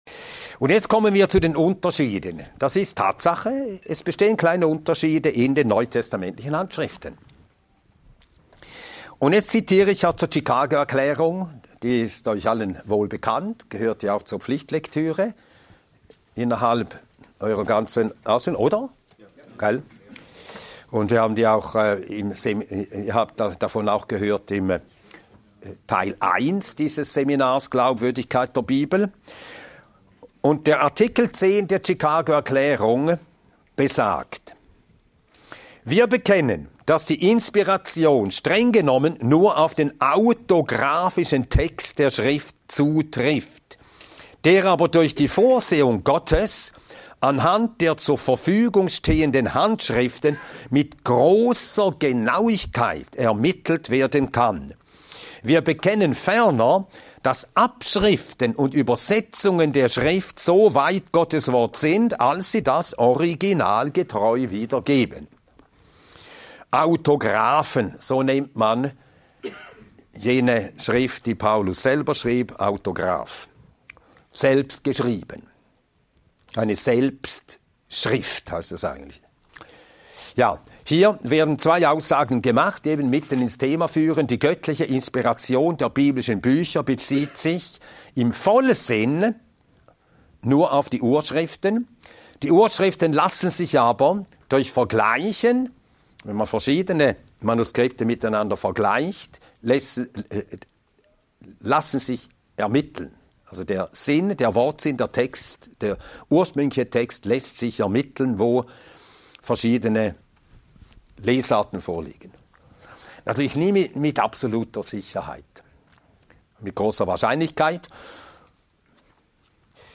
Ein mehrteiliger Vortrag